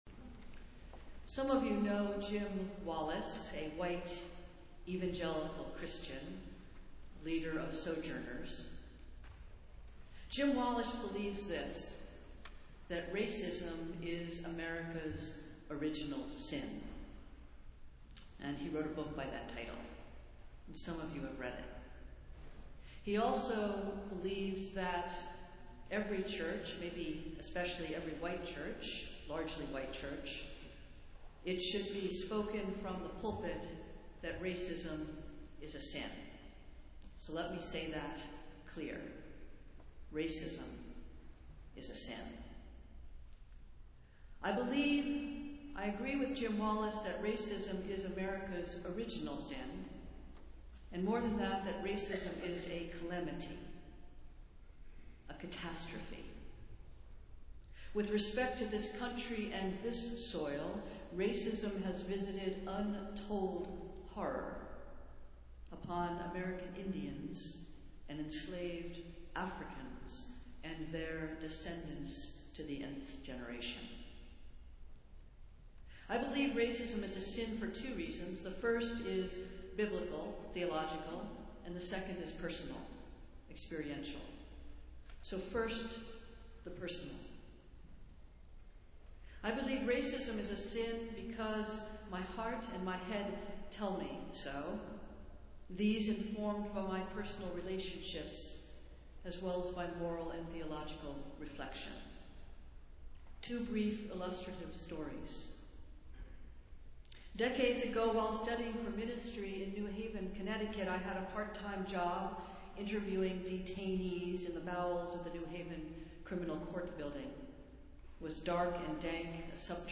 Festival Worship - Phillis Wheatley Sunday